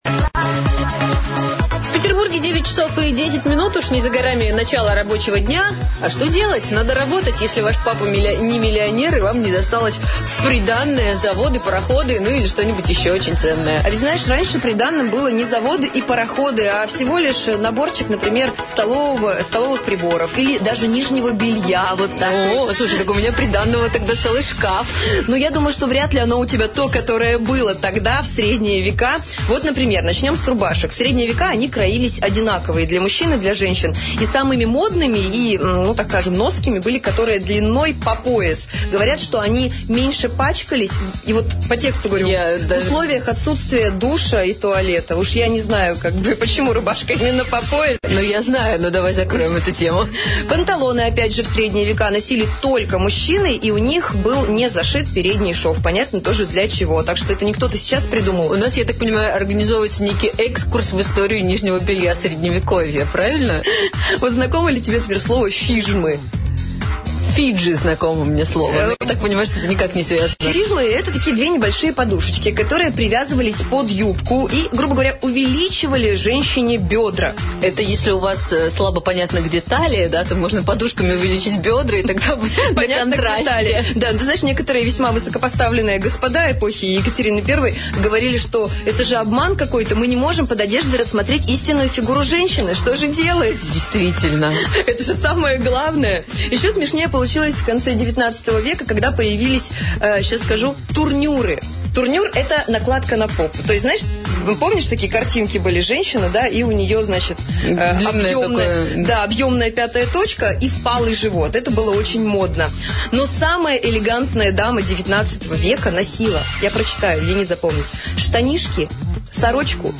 Утреннее шоу "Хорошо" на "Радио для двоих" (Санкт-Петербург). Запись эфира.
Утреннее шоу "Хорошо" на женской питерской радиостанции "Радио для двоих".